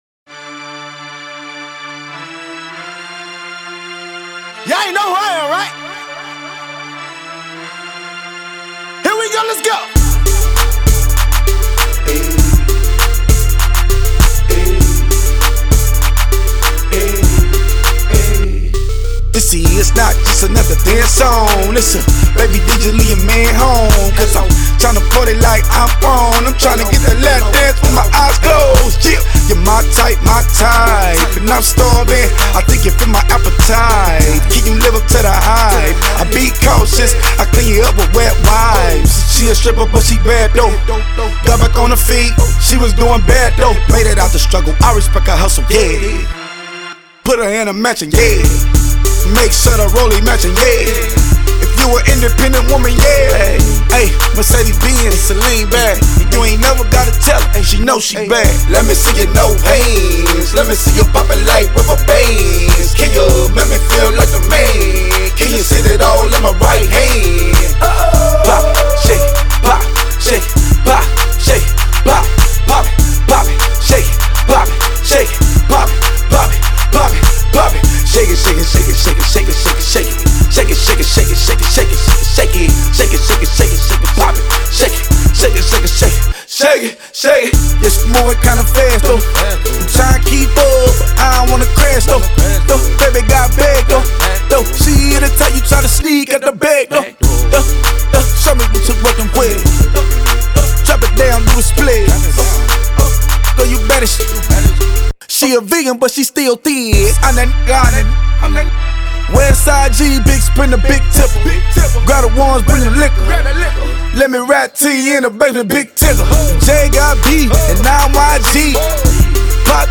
West Coast
club banger